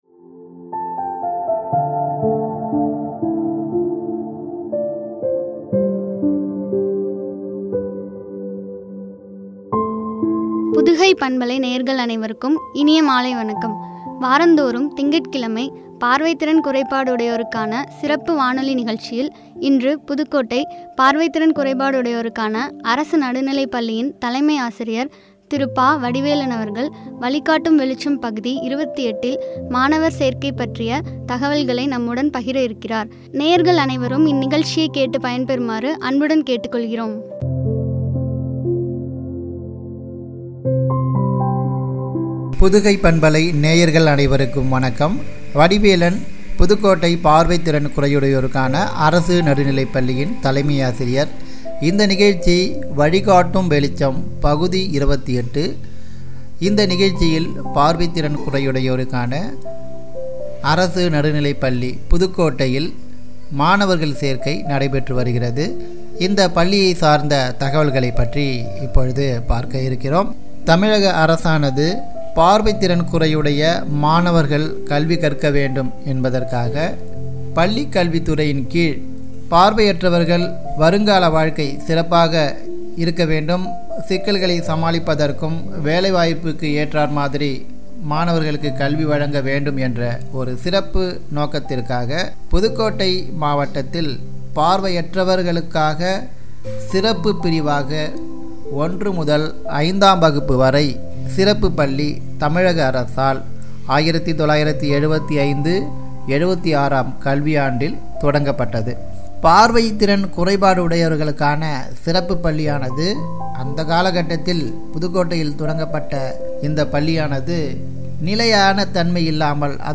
பார்வை திறன் குறையுடையோருக்கான சிறப்பு வானொலி நிகழ்ச்சி
குறித்து வழங்கிய உரையாடல்.